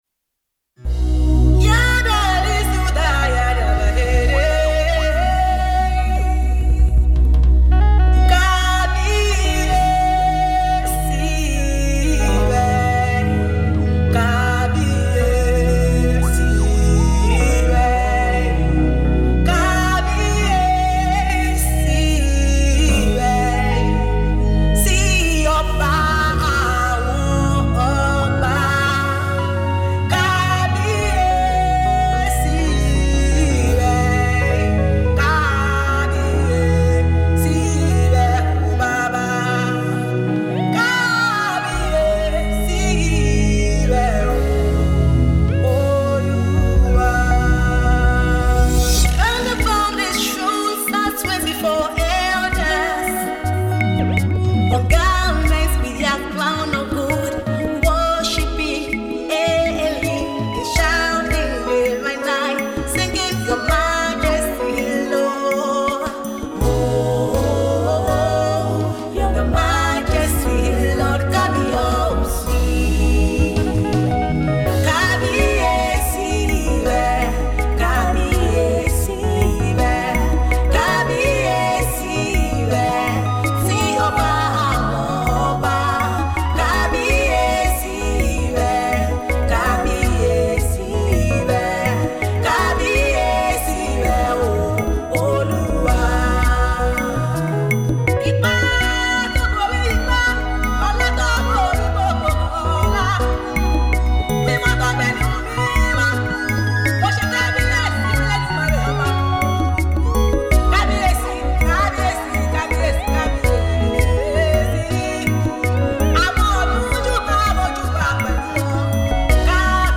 gospel
A songwriter and a singer.